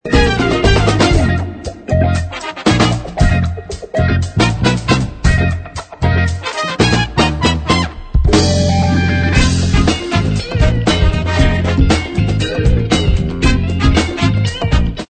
groove influences diverses